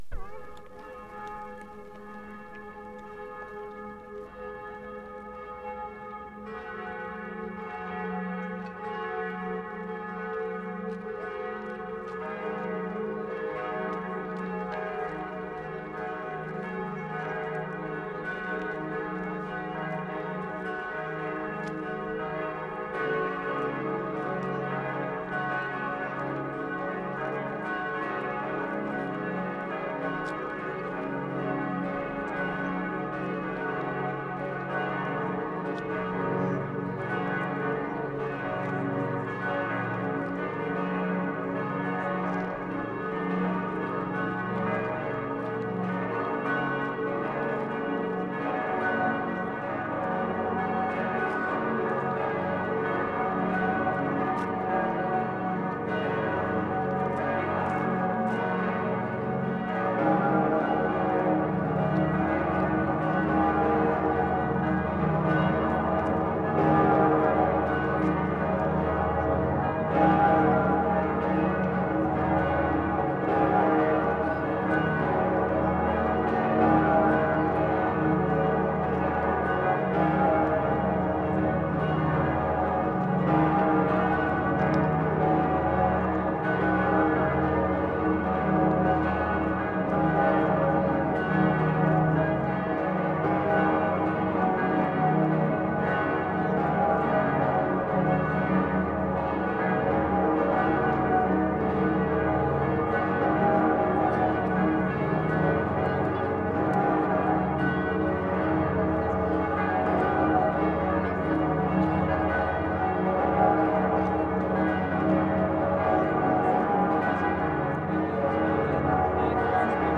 Salzburg, Austria March 23/75
CATHEDRAL BELLS, from middle of Domplatz (Palm Sunday).
3. Almost complete, except for first one or two attacks. Recorded from middle of square, amidst the sounds of people milling about the square, before forming the procession.